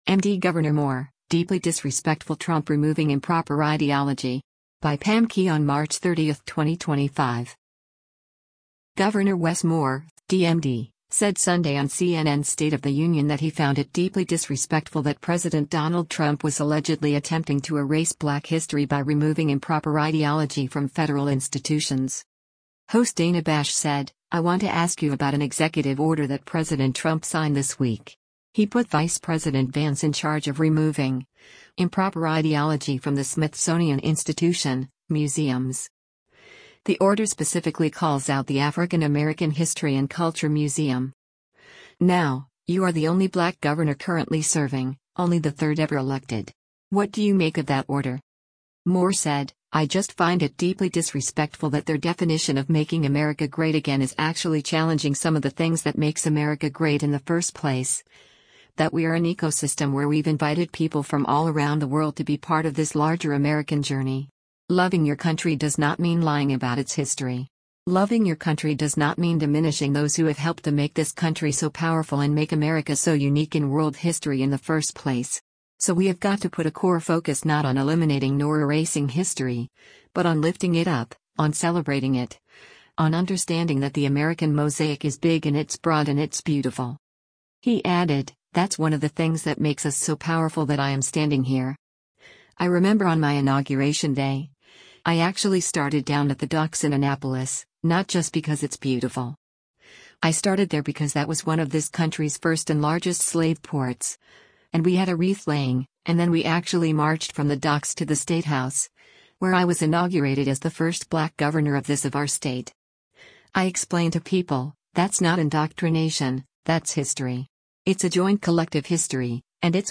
Governor Wes Moore (D-MD) said Sunday on CNN’s “State of the Union” that he found it “deeply disrespectful” that President Donald Trump was allegedly attempting to erase black history by removing “improper ideology” from federal institutions.